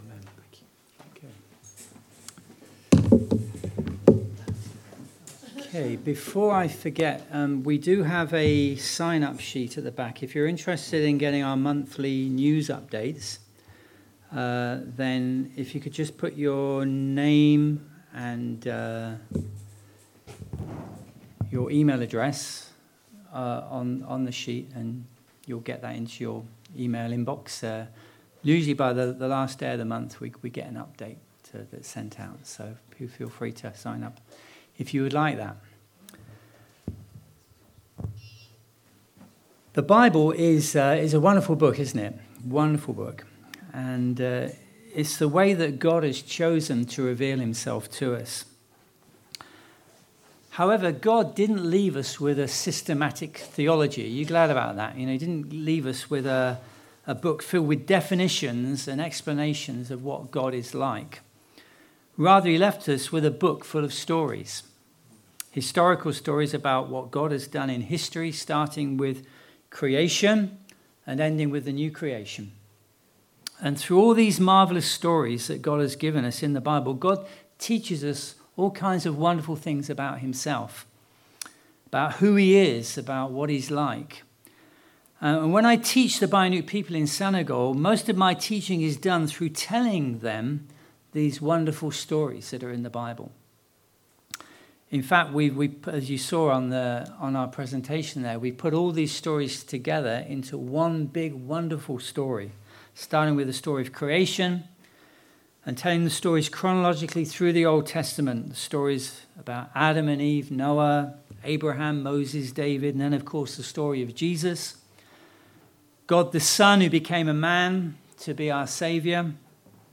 (message begins at 15:15)